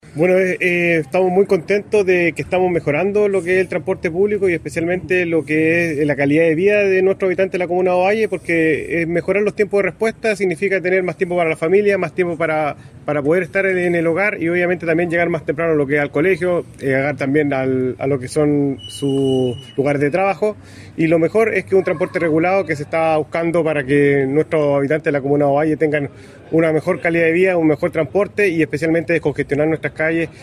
ELECTROMOVILIDAD-OVALLE-Hector-Vega-Alcalde-de-Ovalle.mp3